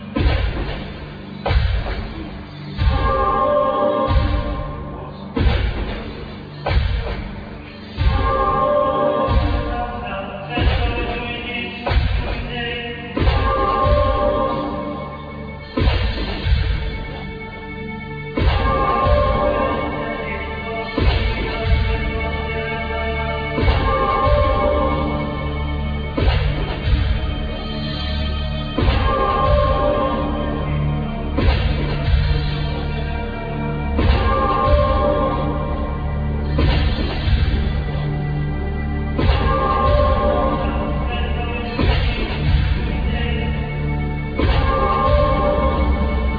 All insturments